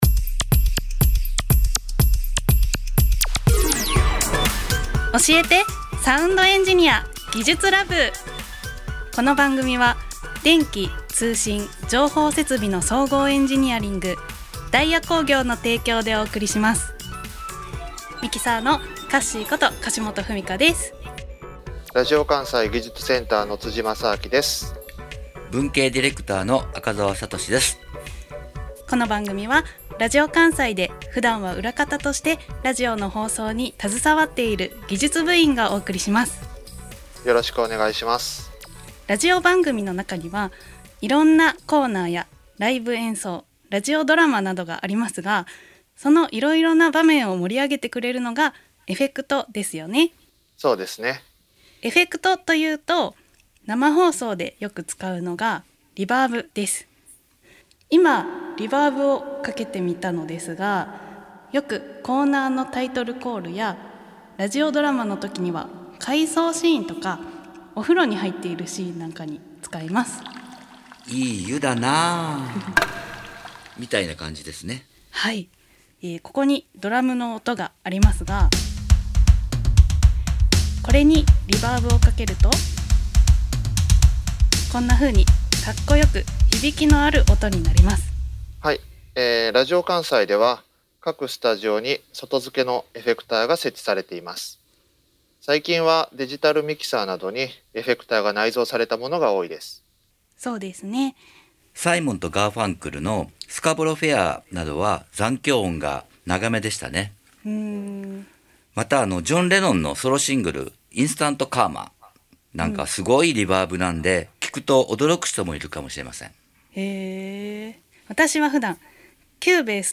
音声メディアのラジオで、音に様々な効果をもたらす「エフェクト」について、普段はラジオの裏方を務める技術スタッフが、ラジオ番組のなかで解説しました。
※ラジオ関西『おしえて！サウンドエンジニア』2021年5月16日放送回音声